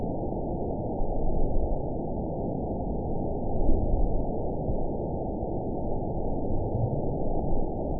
event 919941 date 01/29/24 time 00:11:43 GMT (1 year, 4 months ago) score 5.75 location TSS-AB07 detected by nrw target species NRW annotations +NRW Spectrogram: Frequency (kHz) vs. Time (s) audio not available .wav